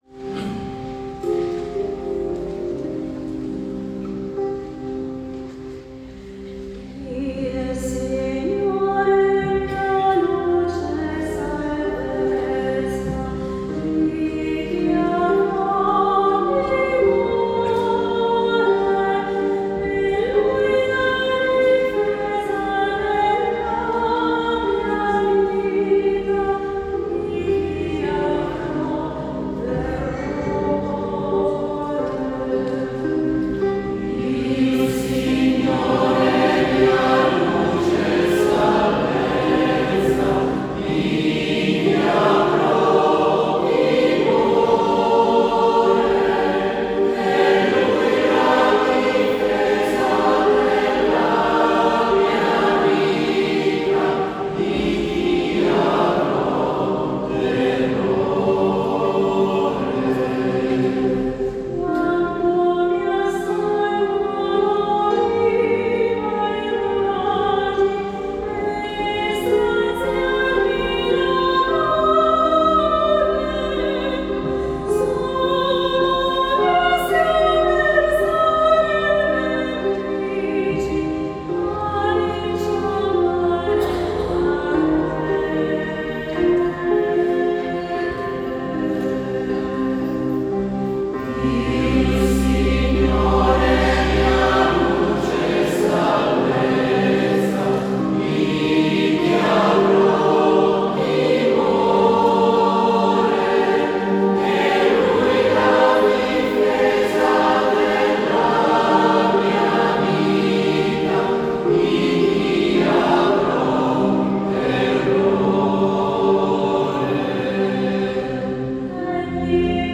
19 aprile 2025 - Notte di Pasqua
Organo
Chitarra
Cimbaletti
Bonghi